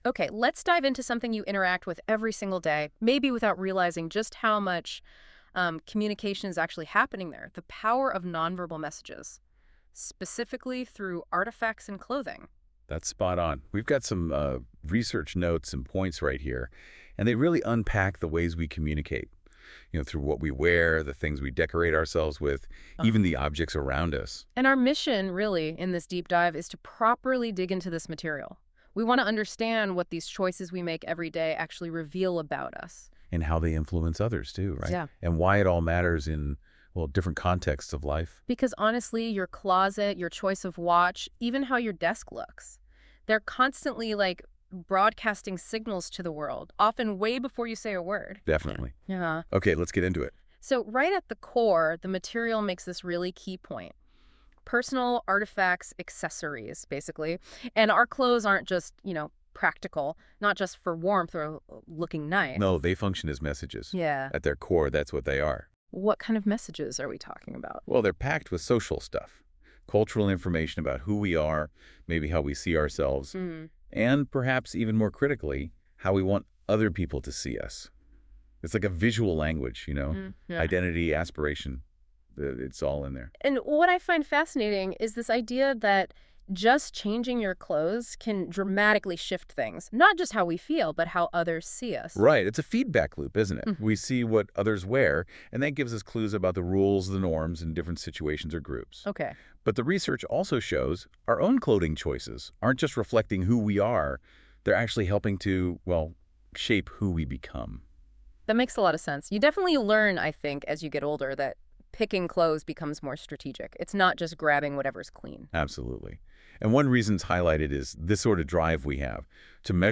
Lecture dialogues in English provided in the Nonverbal Communication course at Ritsumeikan University, Osaka Ibaraki Campus, as a selective class for 13 weeks in the 2025-26 academic year, which have been created using Google NotebookLM, are given here.